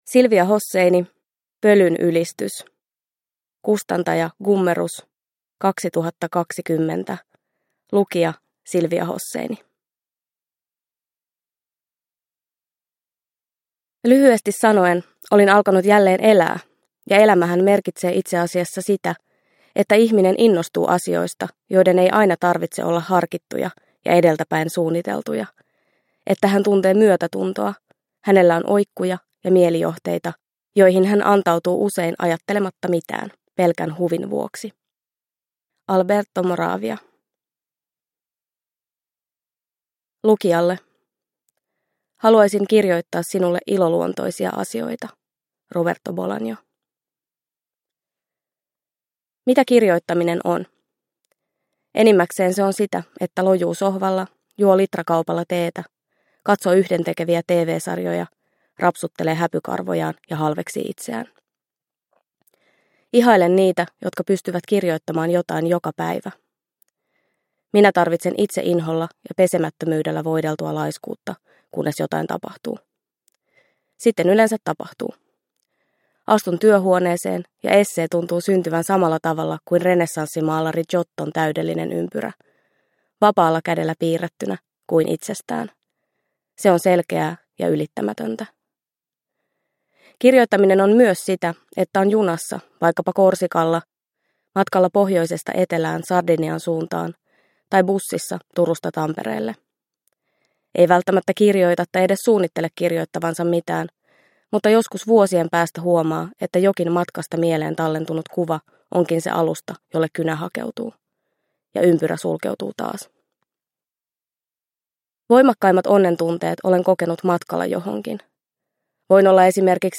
Pölyn ylistys – Ljudbok – Laddas ner